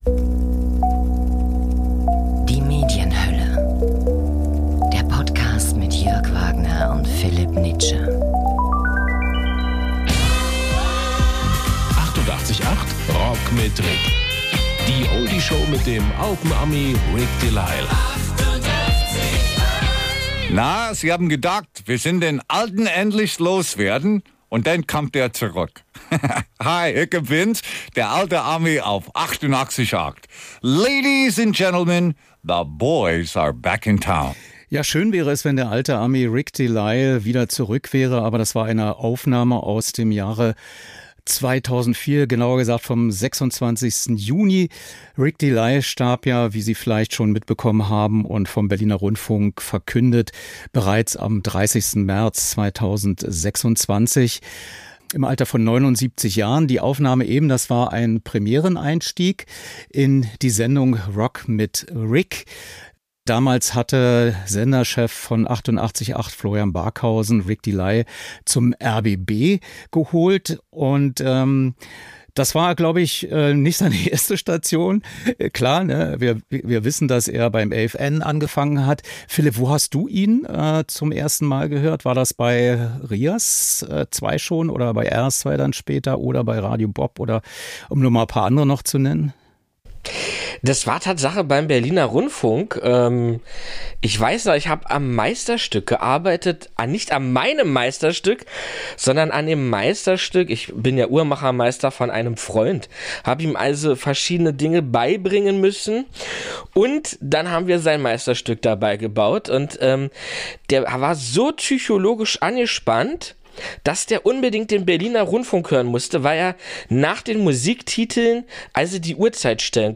Wir präsentieren Rik De Lisle noch einmal mit verschiedenen O-Ton-Fragmenten.